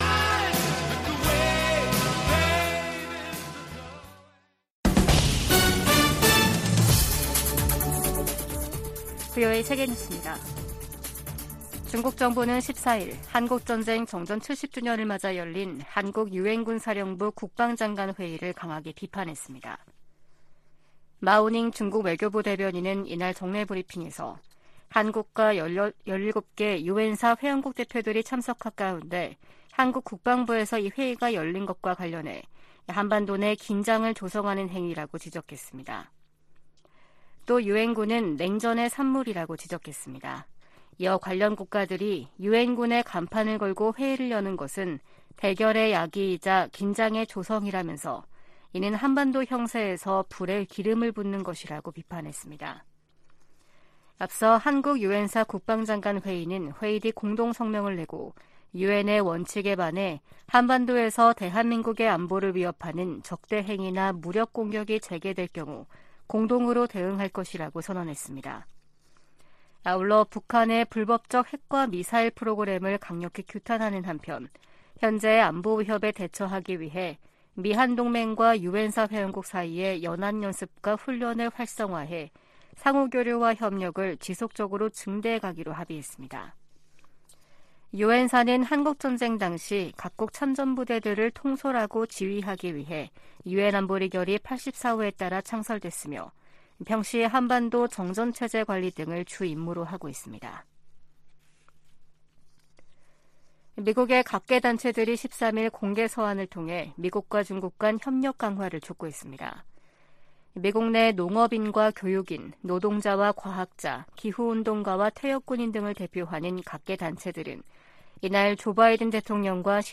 VOA 한국어 아침 뉴스 프로그램 '워싱턴 뉴스 광장' 2023년 11월 15일 방송입니다. 한국을 방문한 로이드 오스틴 미 국방장관은 한반도 평화와 안정에 대한 유엔군사령부의 약속은 여전히 중요하다고 강조했습니다. 미 국무부는 이번 주 열리는 아시아태평양 경제협력체(APEC) 회의를 통해 내년도 역내 협력을 위한 전략적 비전이 수립될 것이라고 밝혔습니다. 15일 미중정상회담에서 양자 현안뿐 아니라 다양한 국제 문제들이 논의될 것이라고 백악관이 밝혔습니다.